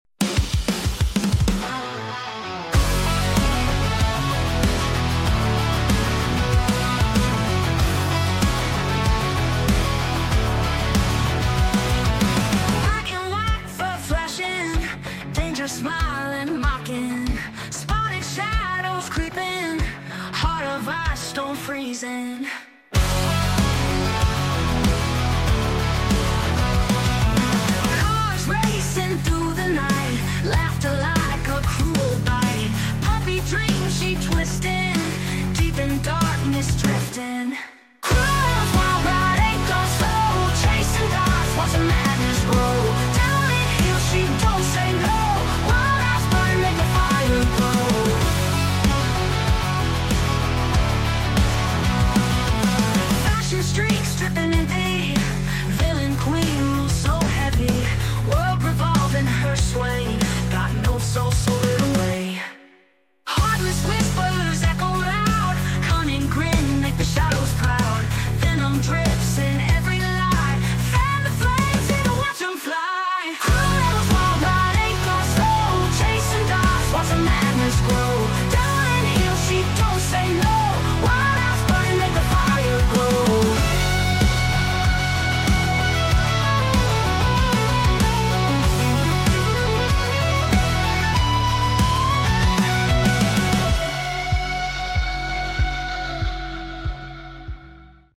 made with Ai!